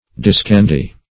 Discandy \Dis*can"dy\